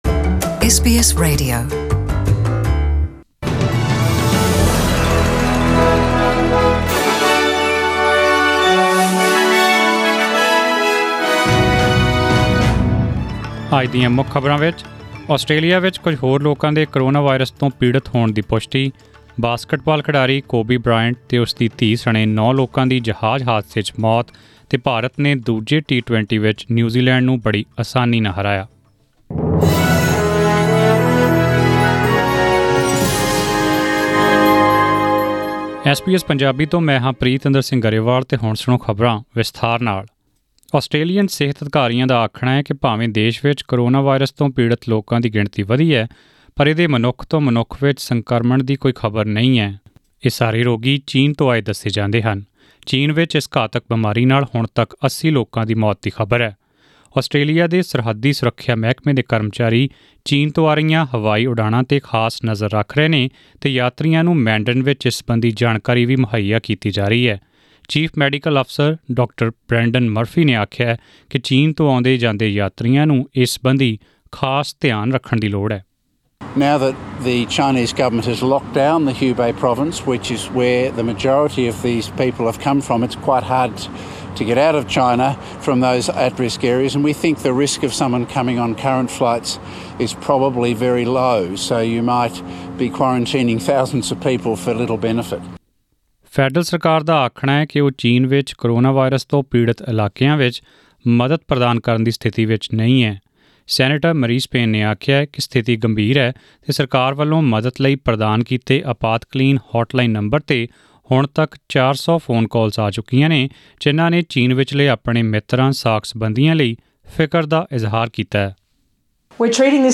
Australian News in Punjabi: 27 January 2020